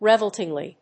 アクセント・音節re・vólt・ing・ly